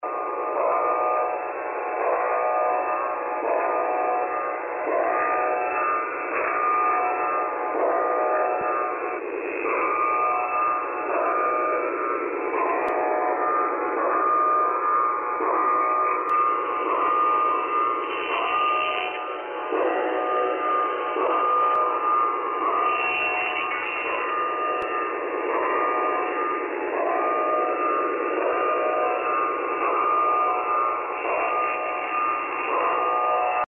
528 Кб 12.01.2025 17:45 Маркерный сигнал на частоте 6914 кГц в несвойственной нижней боковой полосе.